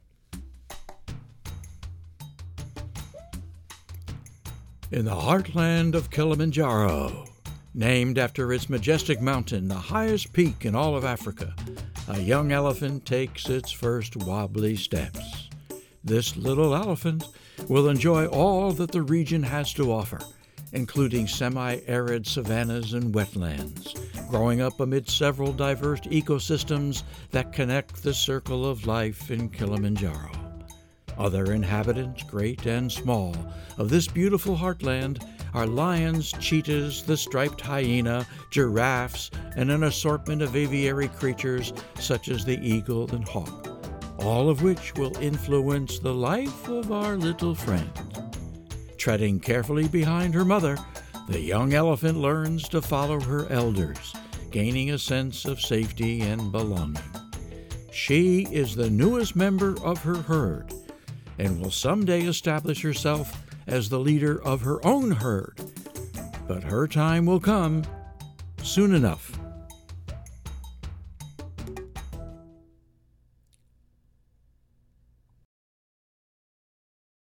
Original Poem
English (North American)
Voice Age
Senior